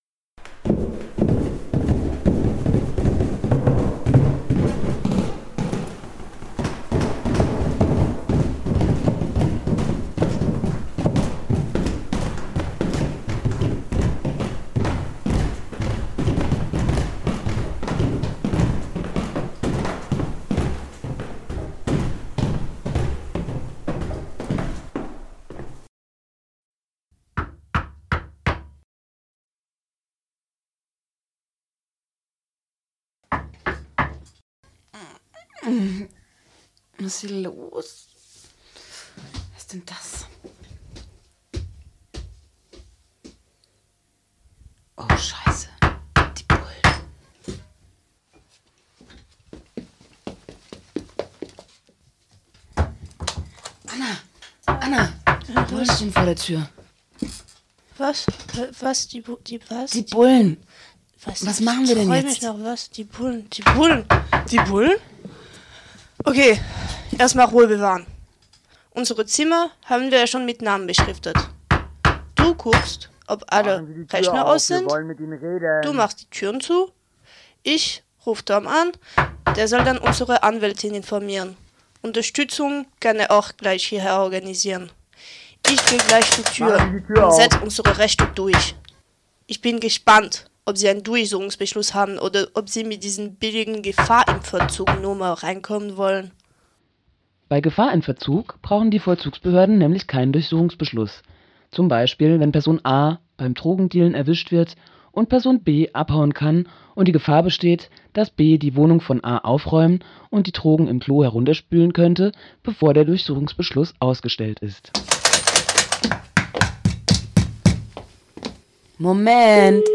Keine Aussage, keine Unterschrift, Wiederspruch einlegen! Hörspielreihe zum Thema Rechtshilfetip